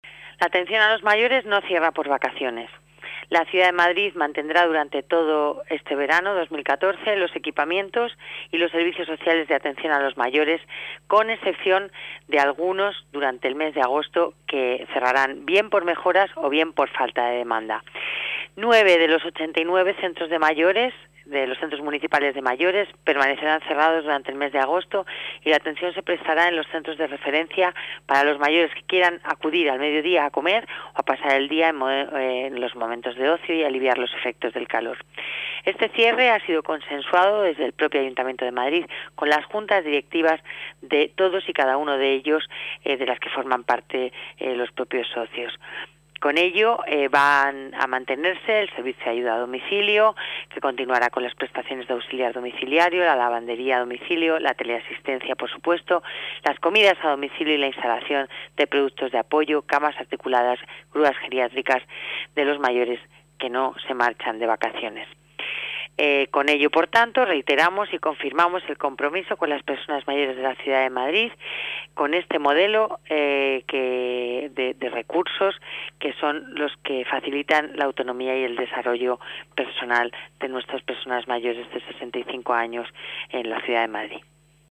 Nueva ventana:Declaraciones de la delegada de Familia, Servicios Sociales y Participación Ciudadana, Lola Navarro: Funcionamiento centros de mayores